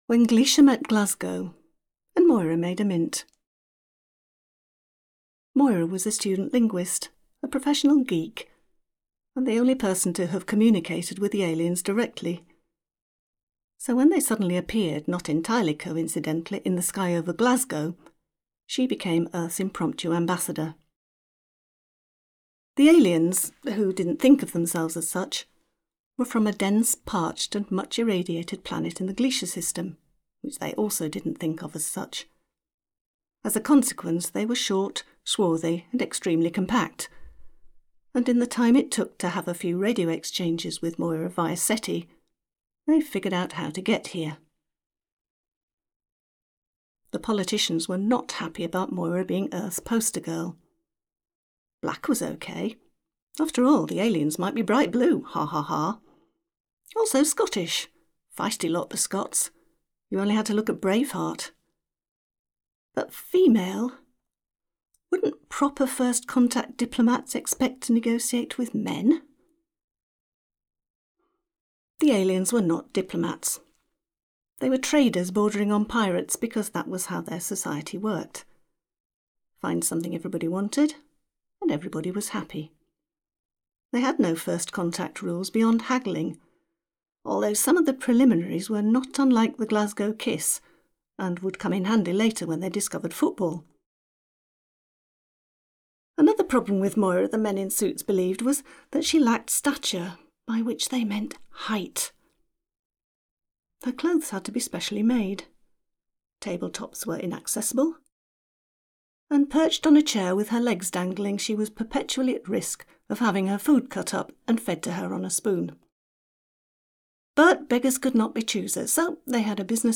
author narration
And the voices will be the authors’ own.